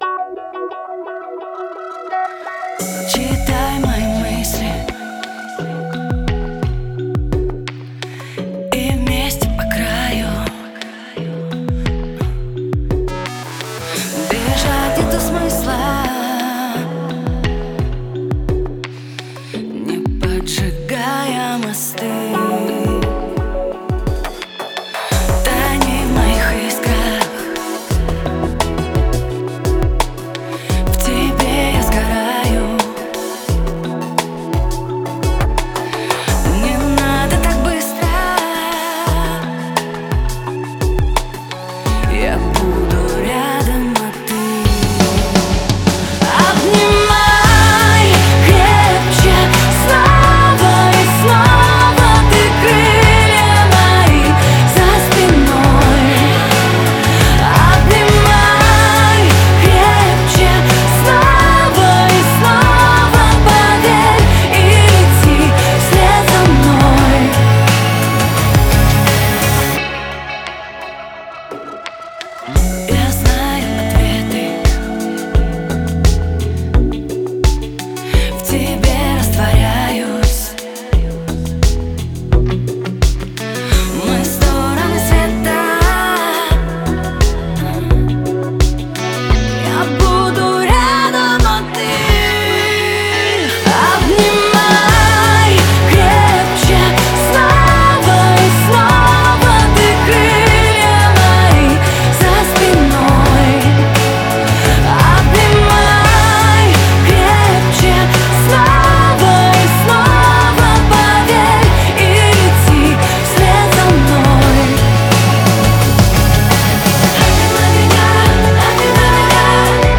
Немного поп музыки )
низа не хватает, ни бочки, ни баса.
В припеве бы потише гитарки. А то вокал тонет в них.
Рокопопс)